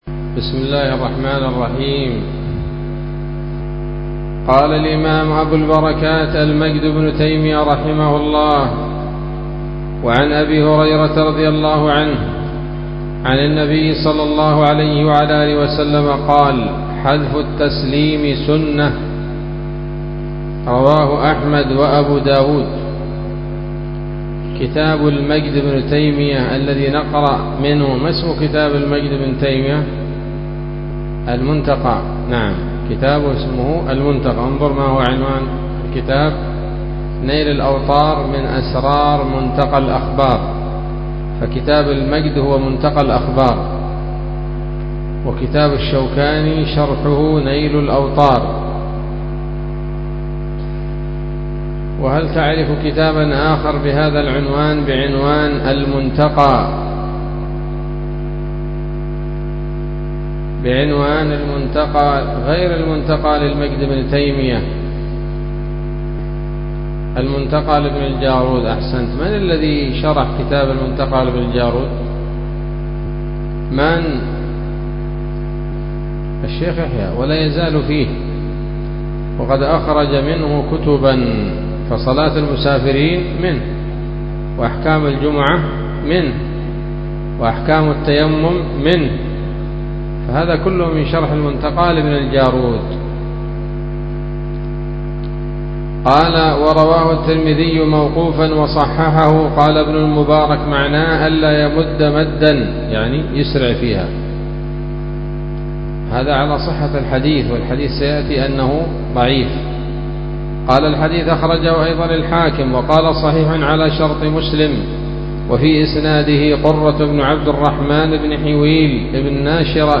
الدرس الثالث والتسعون من أبواب صفة الصلاة من نيل الأوطار